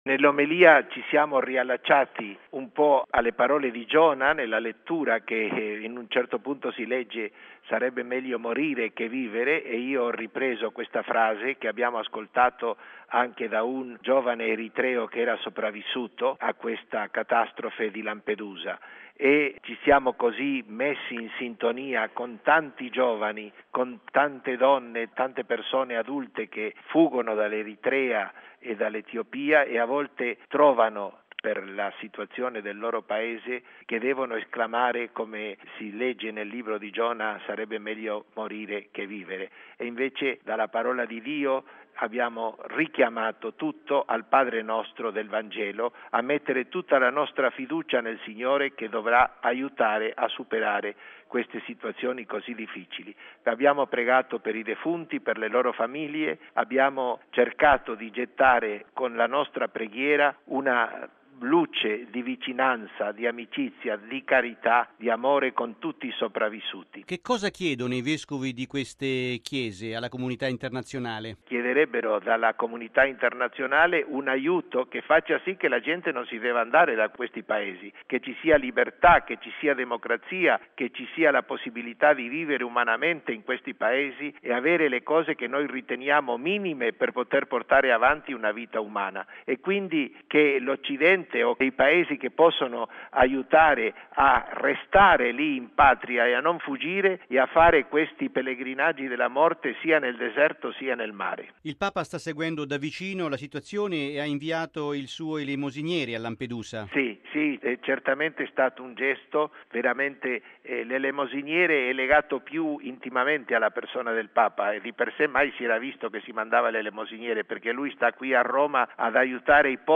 ha intervistato il cardinale Sandri: